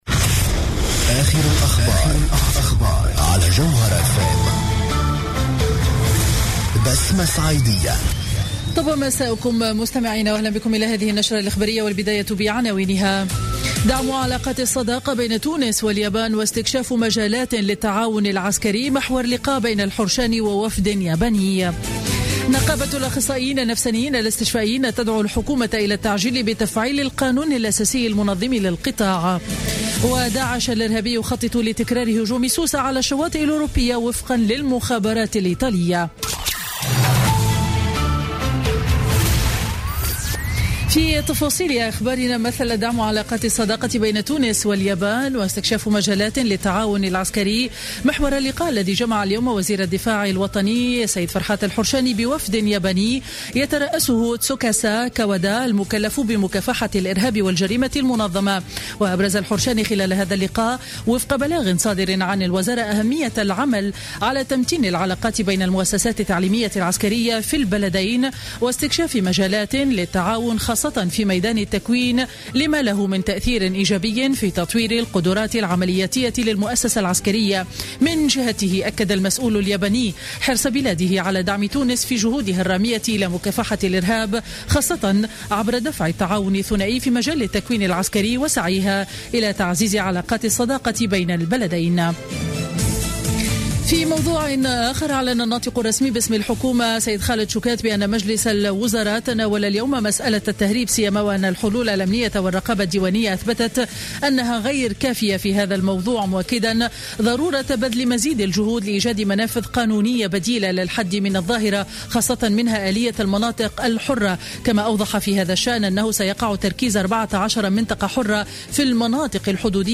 نشرة أخبار السابعة مساء ليوم الثلاثاء 19 أفريل 2016